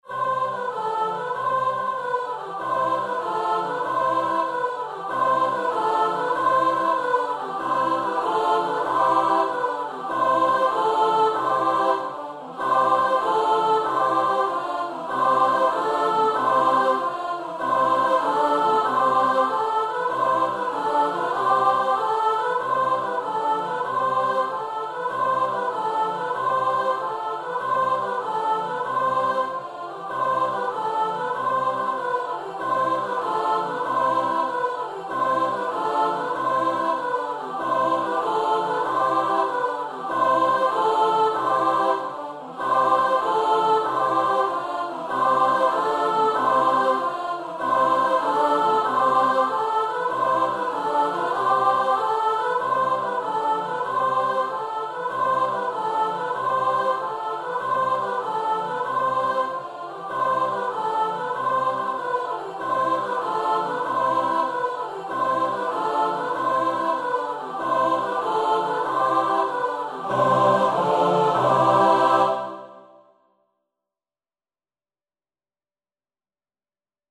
Free Sheet music for Choir
C major (Sounding Pitch) (View more C major Music for Choir )
Happily .=c.96
3/8 (View more 3/8 Music)
Choir  (View more Easy Choir Music)
Classical (View more Classical Choir Music)